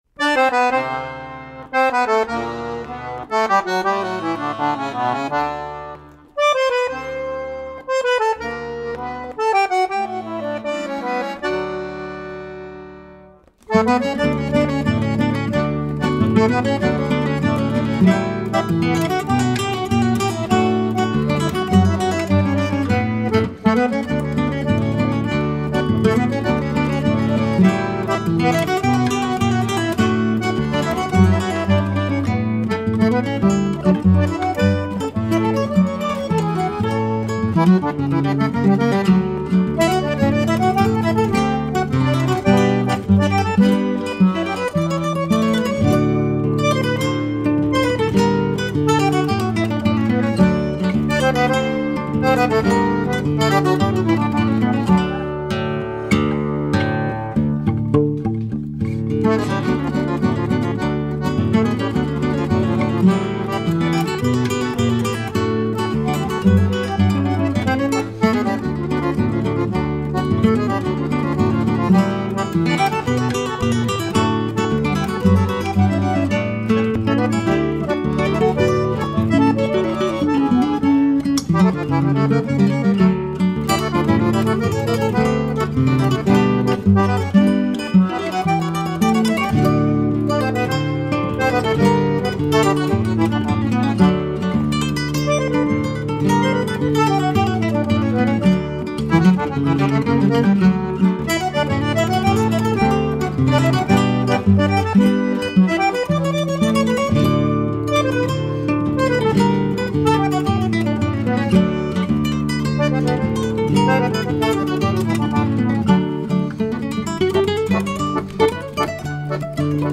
1285   03:34:00   Faixa:     Baião
Violao 7
Acoordeon